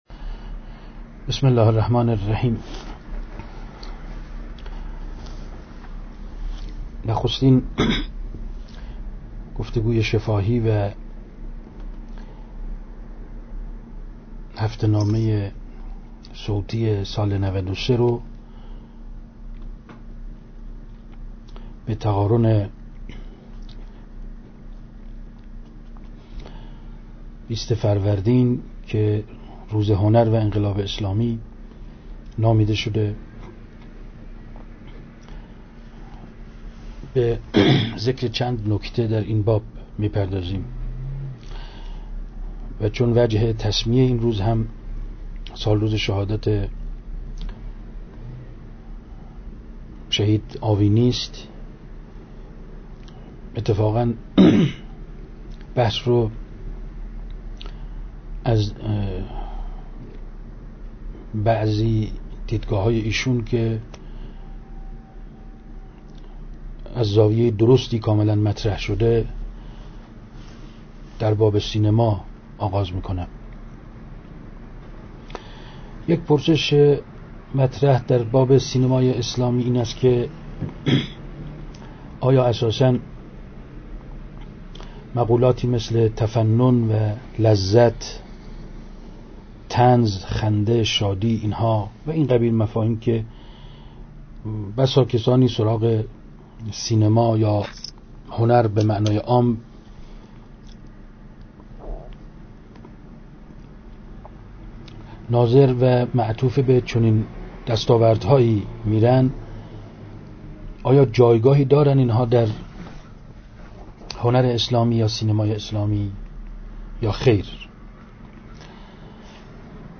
به مناسب «روز هنر انقلاب اسلامی» ، سالروز شهادت شهید آوینی
مقاله صوتی شماره 35